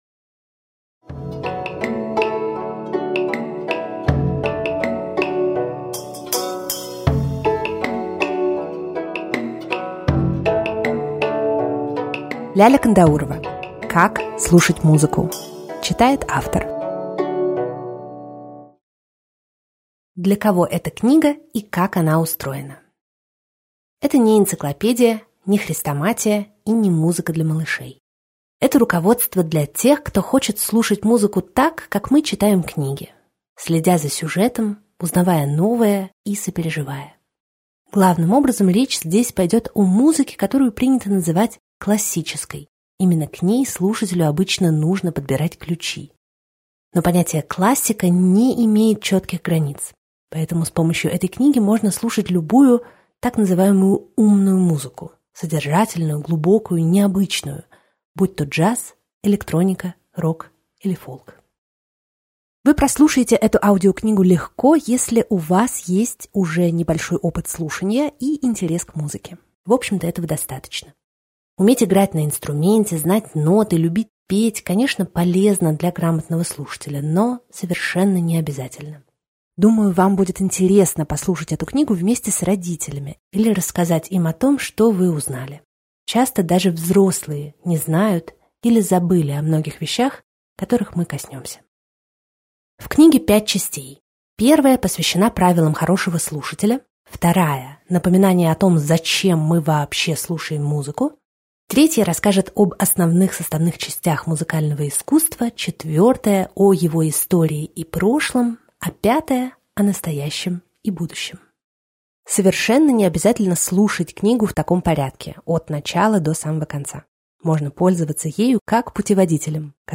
Аудиокнига Как слушать музыку | Библиотека аудиокниг